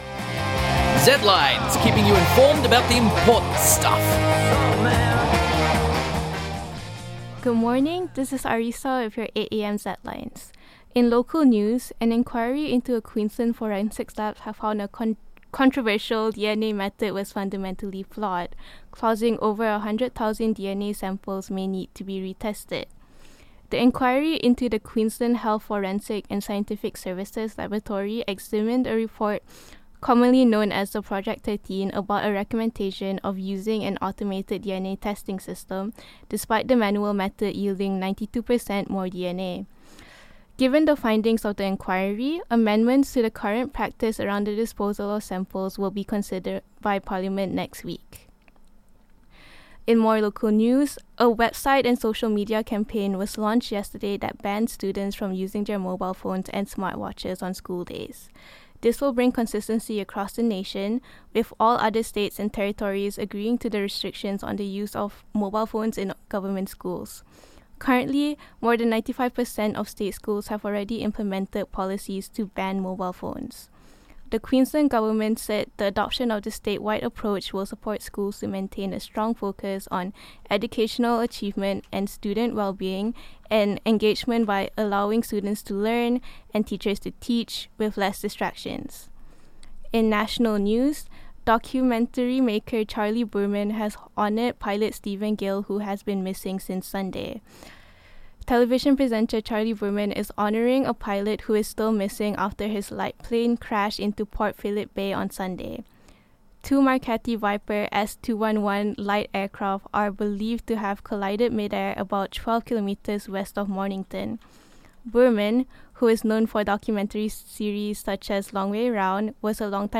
Image Credit: Prime Minister Boris Johnson Portrait (MoD Crown Copyright/Wikimedia Commons under CC3.0) Zedlines Bulletin ZEDLINES 8AM 211123.mp3 (3.5 MB)